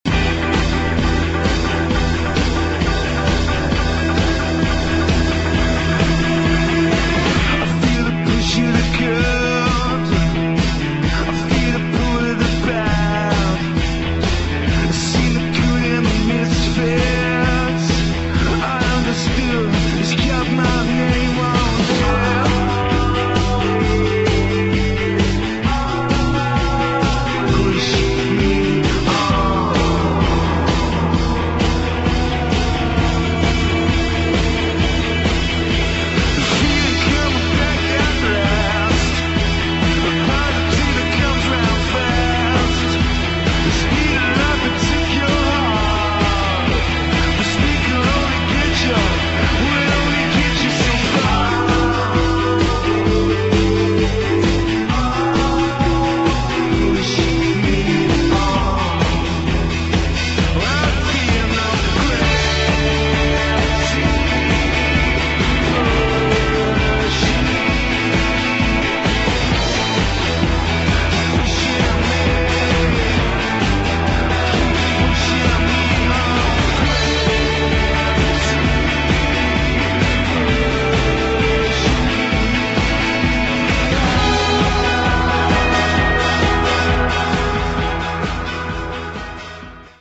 [ ROCK | DISCO ]